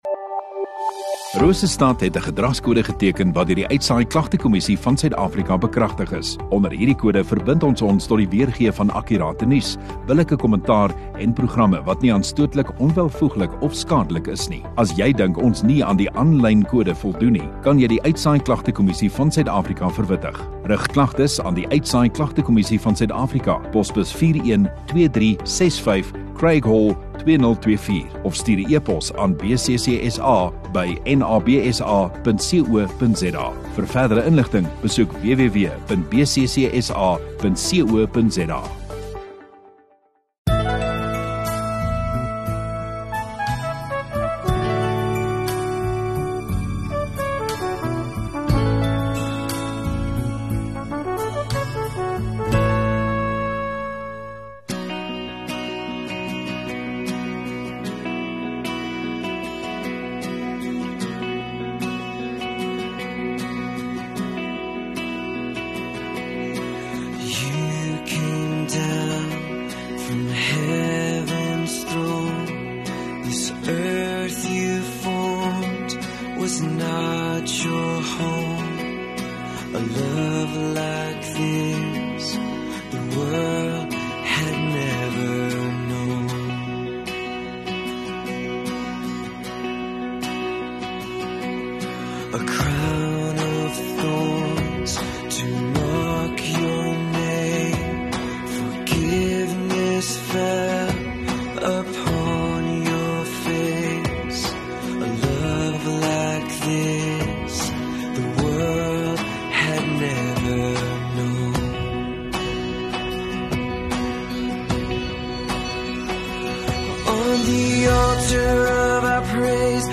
6 Oct Vrydag Oggenddiens